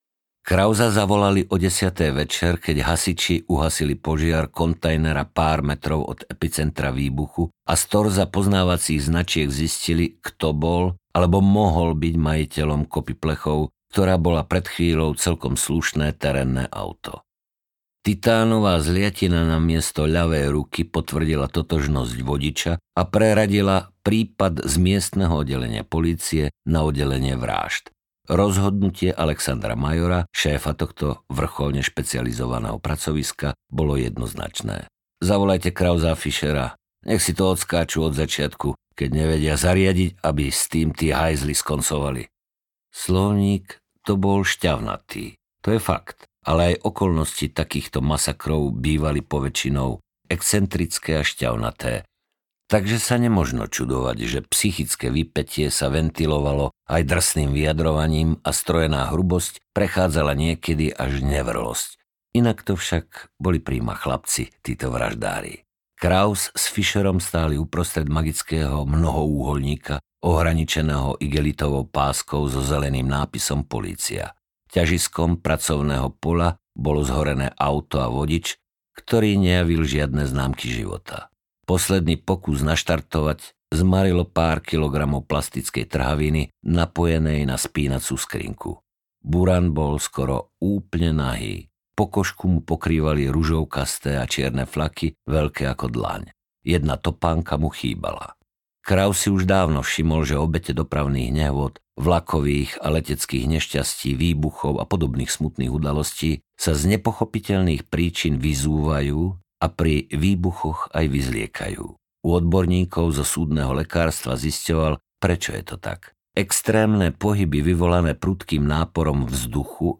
Nehanebné neviniatko audiokniha
Ukázka z knihy
• InterpretMarián Geišberg